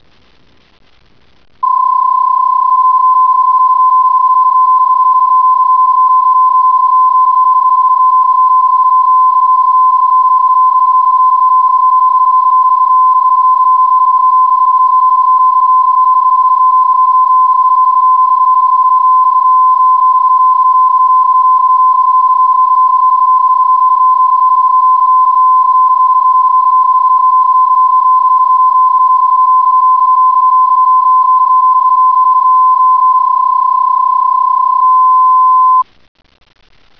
So erkennt ihr die Signale auf den Frequenzen (einige Hörbeispiele).
Fernschreiben, Text, Telegrafie, etc.
PSK31
psk31.wav